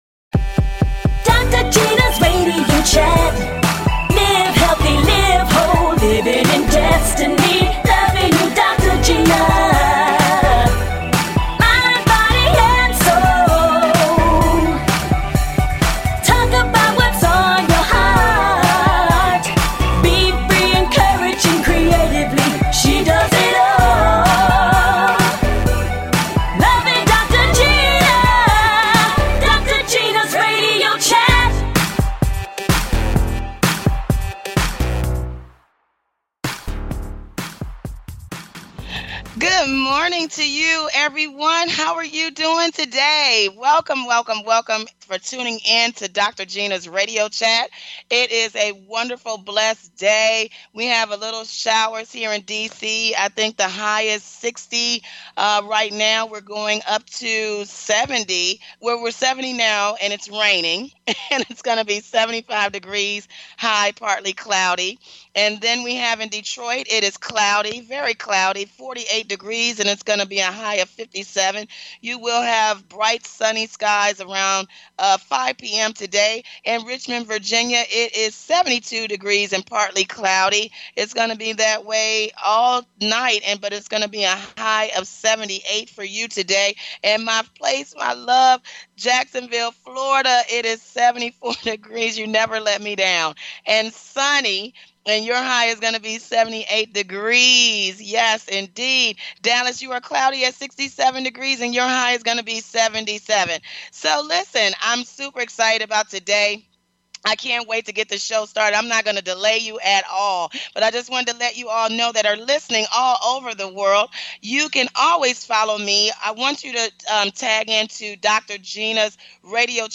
Talk Show
And full of laughter!